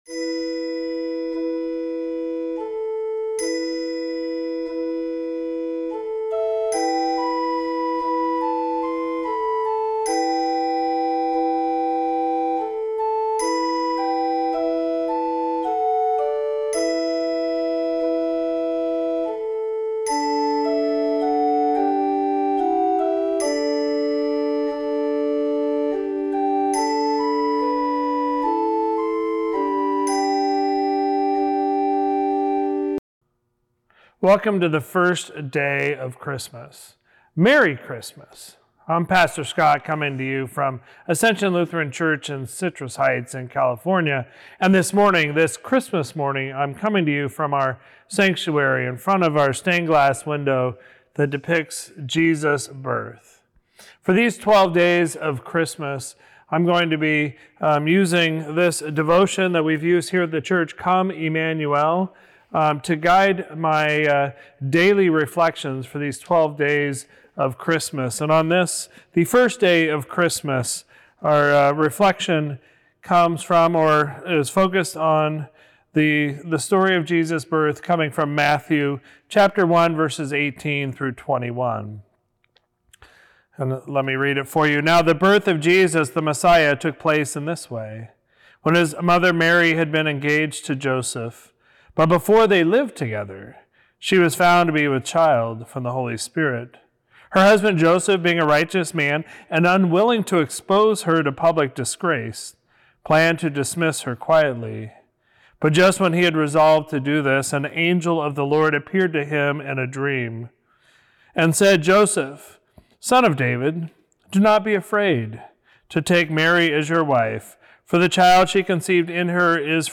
On each of the Twelve Days of Christmas (December 25 to January 5), Ascension Lutheran Church of Citrus Heights will share a short reflection. The theme each day relates to that day’s devotion in Come, Emmanuel: Devotions for Advent & Christmas 2023-24, published by Augsburg Fortress.